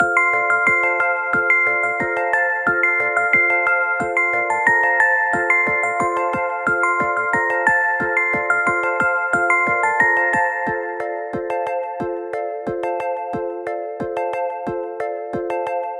甘くてかわいいBGMです！
ループ：◎
BPM：180 キー：F ジャンル：あかるい 楽器：オルゴール、シンセサイザー